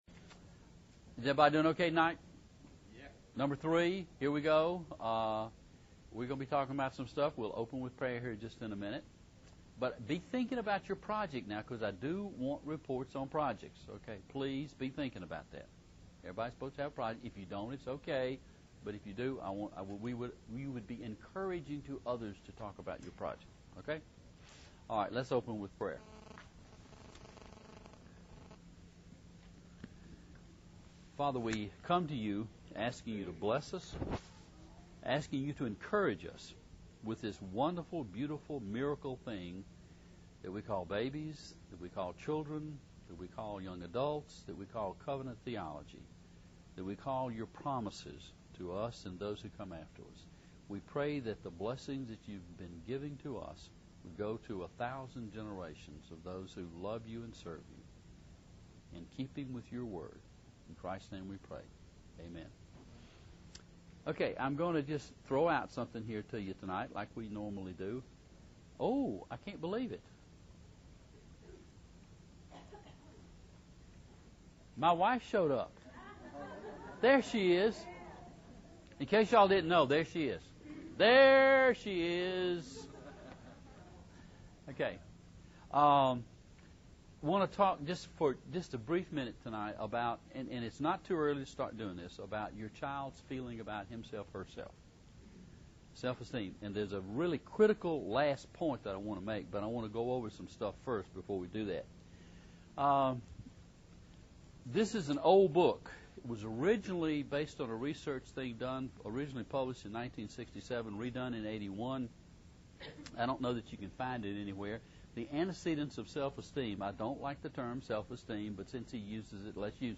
Parenting Seminar